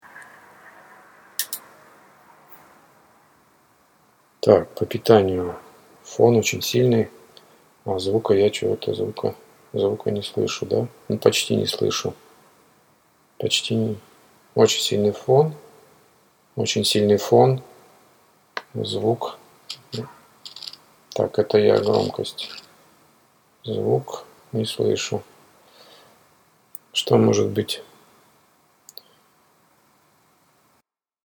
Микрофон M-Audio Luna - шумит. Что это может быть?
Доброго времени суток. Мб кто и подскажет, что может шуметь в микрофоне? Достался старичок M-Audio Luna, он же Sterling Audio 55 и Gruve Tubes GT55, только без паттернов При включении з вкуковую карту или в блок фантомного питания появляется низкочастотный шум первым делом...